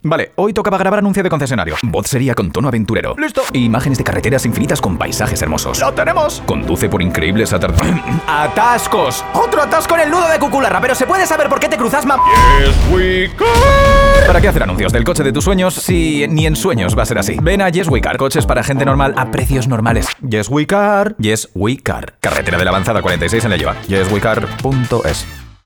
Cuña nada convencional